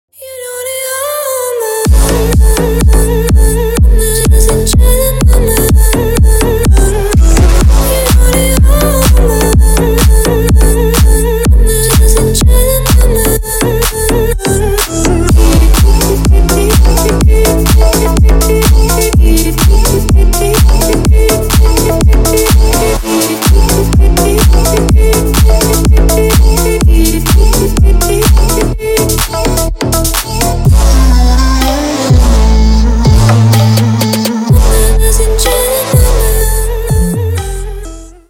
• Качество: 320 kbps, Stereo
Поп Музыка
клубные